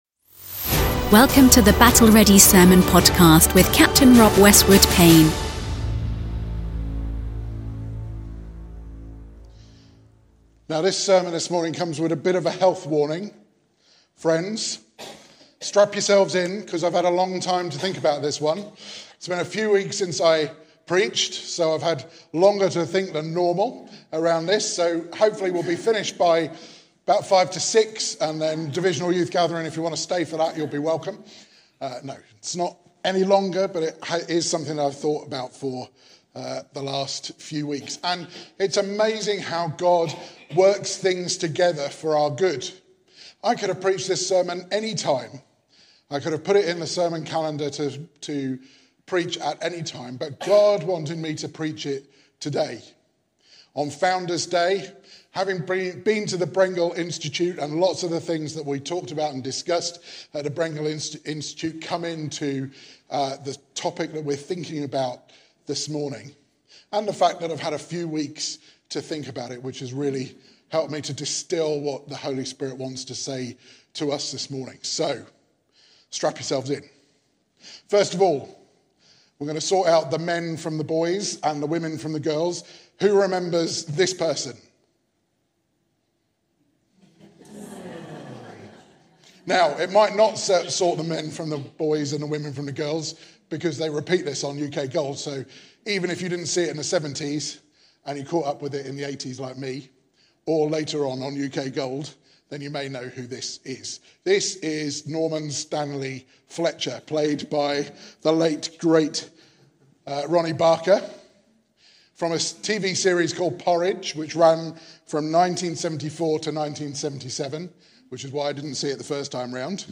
Battle Ready Sermons Prison Life?